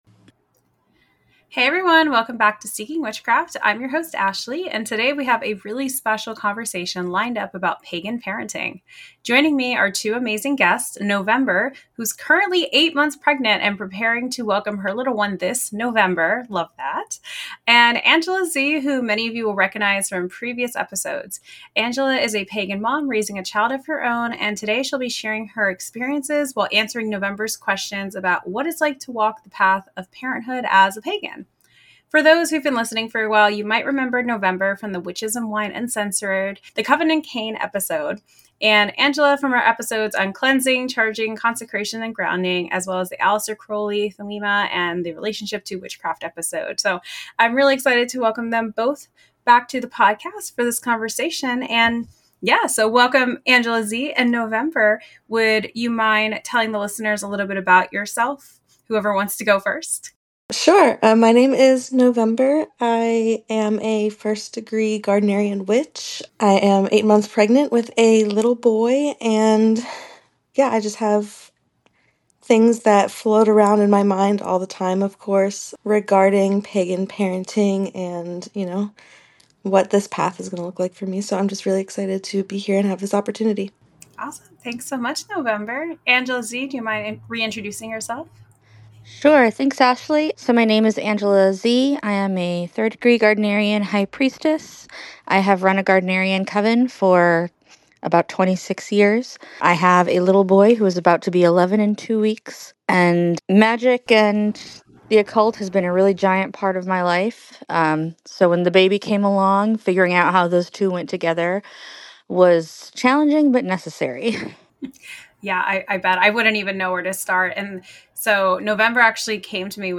Together, they dive into a warm and insightful conversation about parenting through a pagan lens.